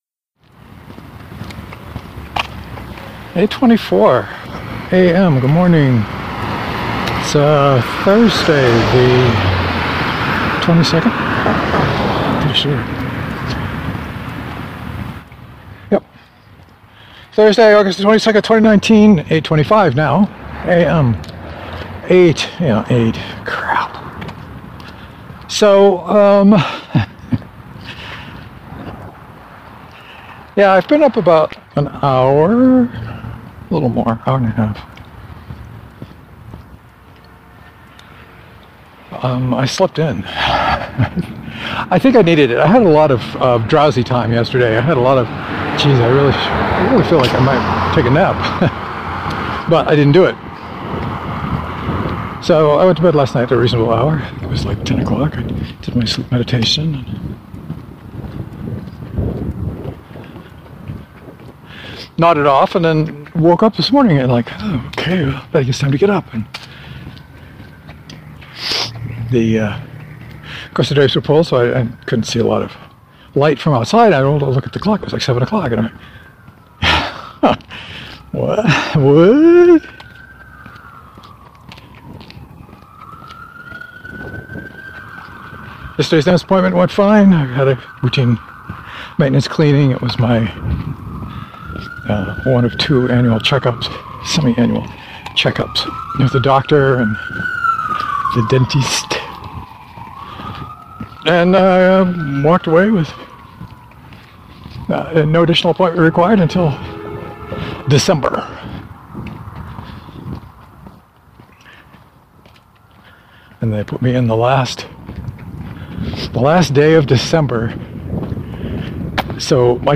Stay on after the sweet good-bye and hear the metronome beating. Nice pace.